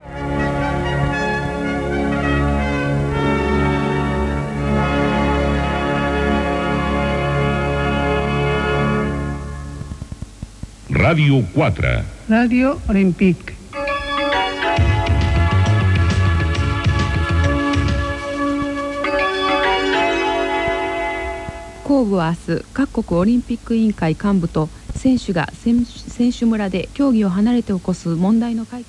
62e2c1cc991d5602e38cdc6edea576f3f8b93d3f.mp3 Títol Ràdio 4 la Ràdio Olímpica Emissora Ràdio 4 la Ràdio Olímpica Cadena RNE Titularitat Pública estatal Descripció Indicatiu i inici de l'informatiu en japonès.